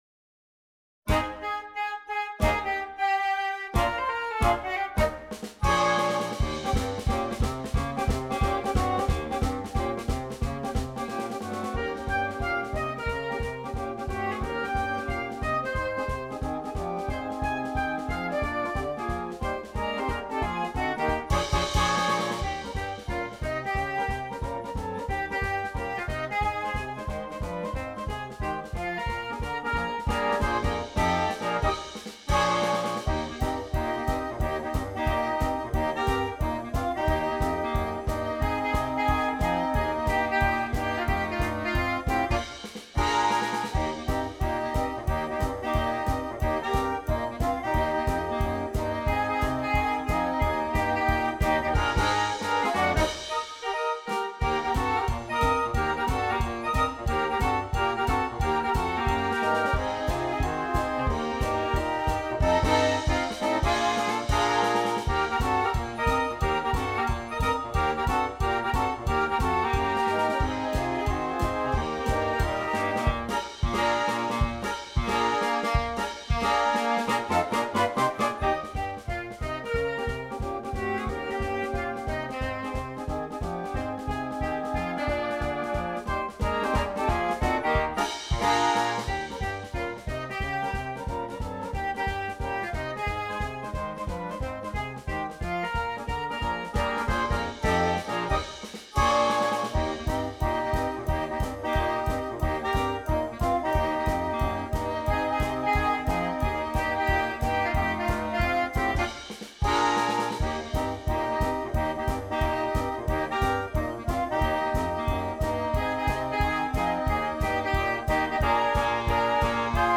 arranged in the style of rockabilly artists
This is a super fun chart for a mixed woodwind ensemble.